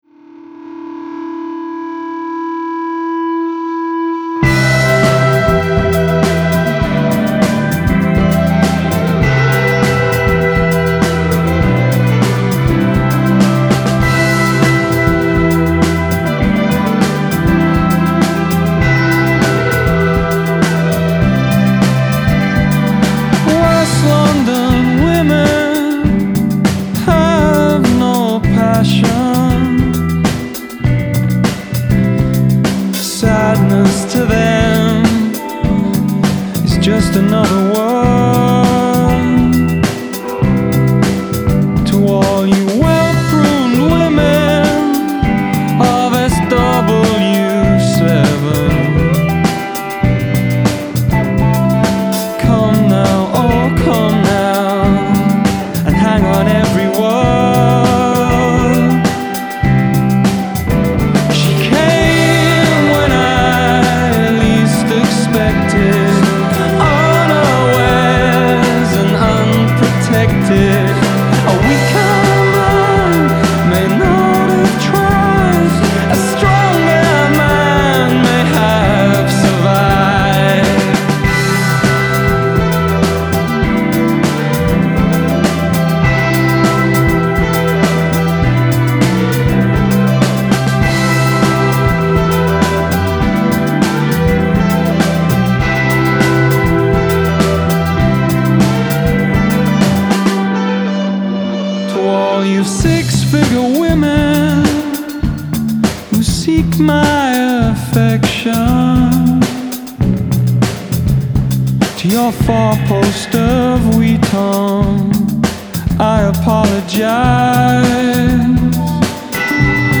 fluid, minor-key lamentation